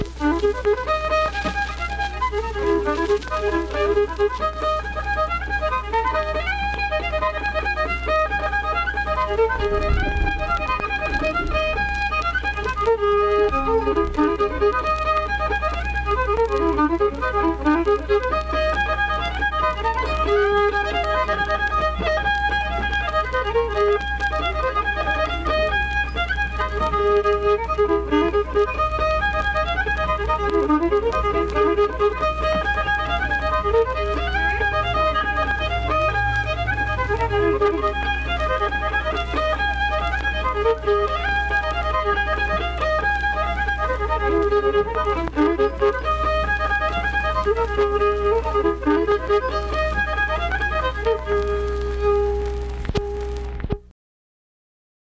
Unaccompanied vocal performance
Voice (sung)
Vienna (W. Va.), Wood County (W. Va.)